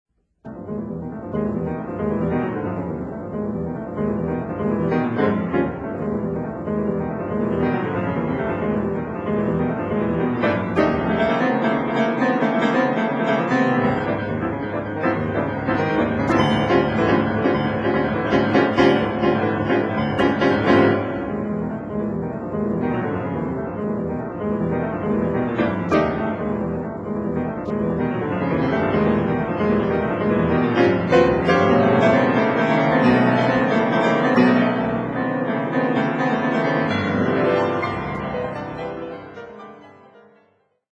CDタイトル 商品番号 曲番 曲　名 作　曲 ピアノ